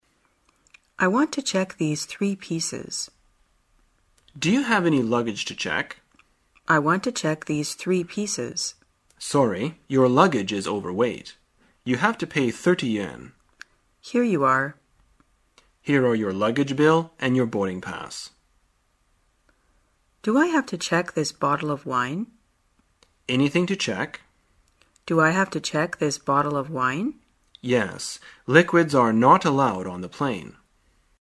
在线英语听力室生活口语天天说 第109期:怎样托运行李的听力文件下载,《生活口语天天说》栏目将日常生活中最常用到的口语句型进行收集和重点讲解。真人发音配字幕帮助英语爱好者们练习听力并进行口语跟读。